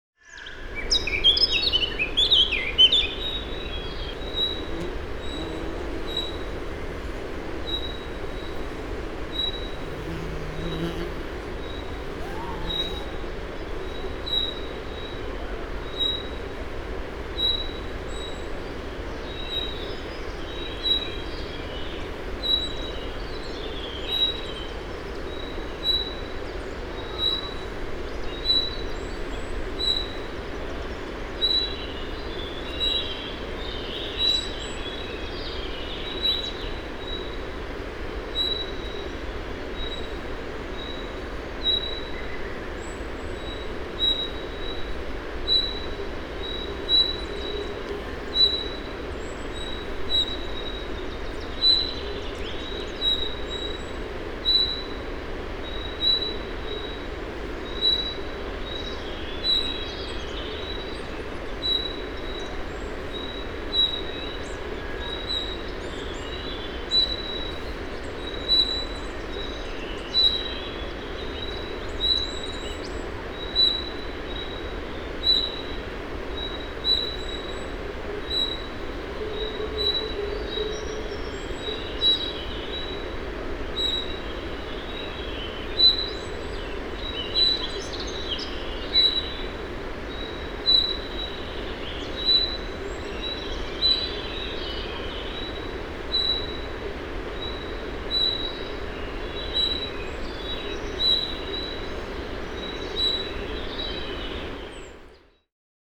Am Voralpsee, Punkt.
voralpsee.mp3